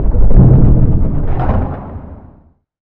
moontruckwork2.wav